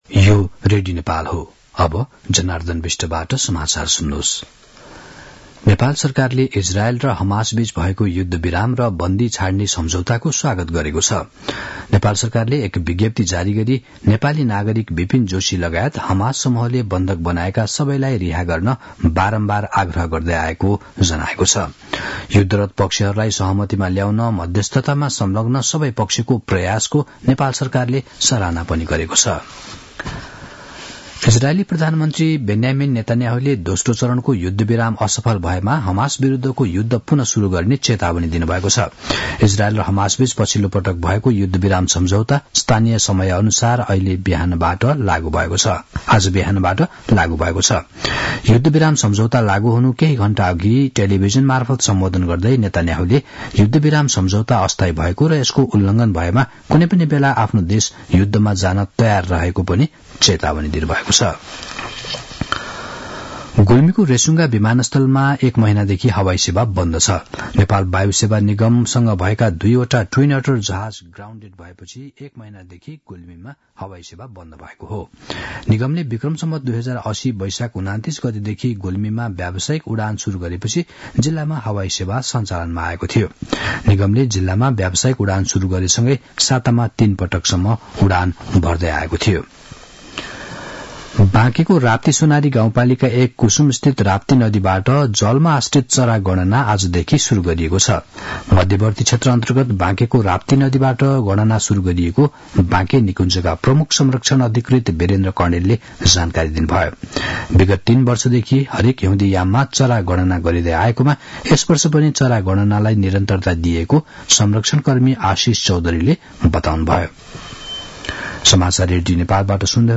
दिउँसो १ बजेको नेपाली समाचार : ७ माघ , २०८१
1-pm-news-1-7.mp3